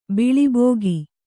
♪ biḷi bōgi